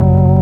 ORG SMALL 0I.wav